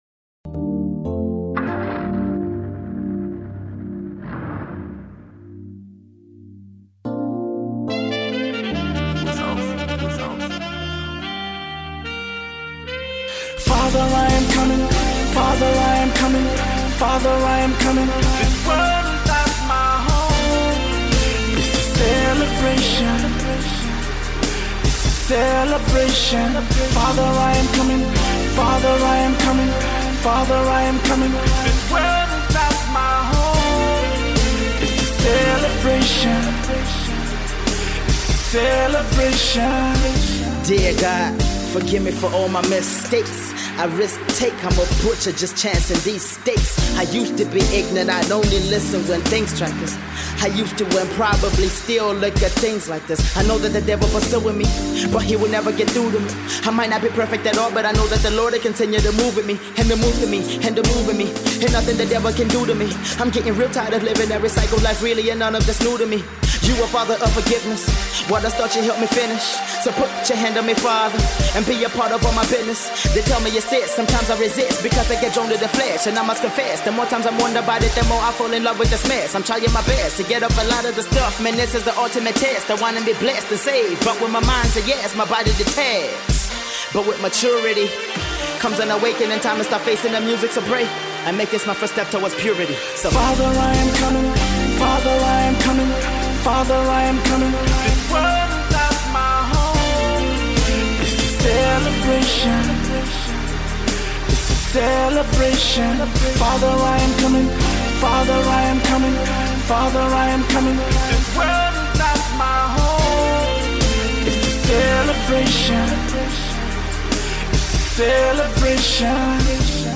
, and the dope second flow!